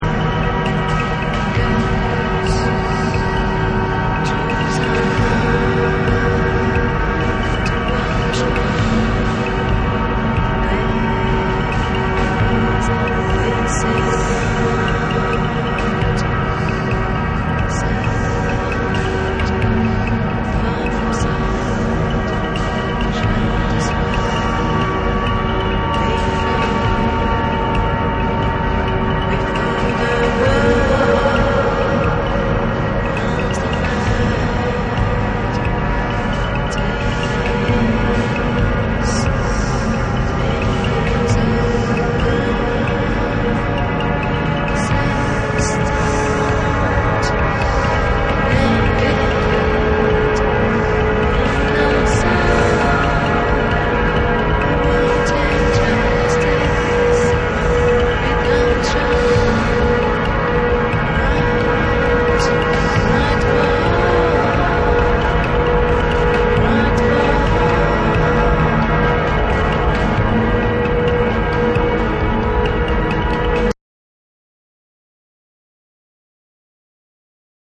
トランシーなシタールの響きが高揚感を与えてくれる
ORGANIC GROOVE / NEW WAVE & ROCK